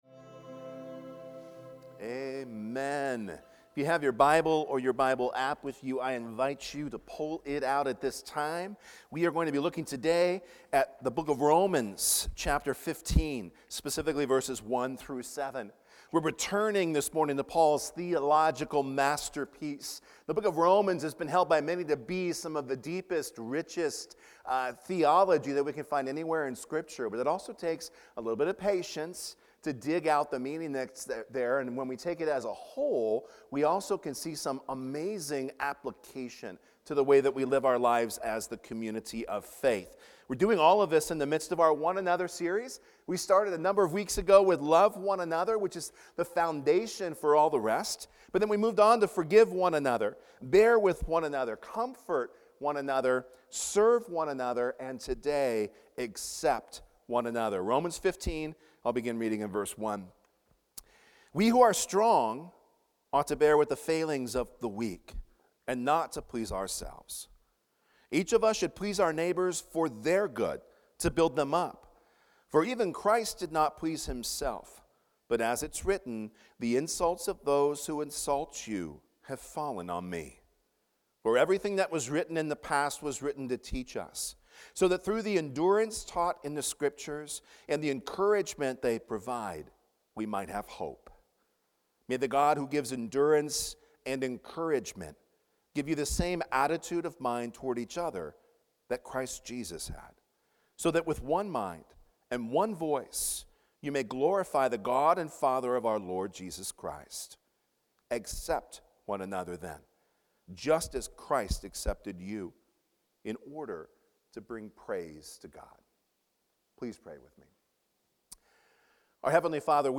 Accept One Another | Fletcher Hills Presbyterian Church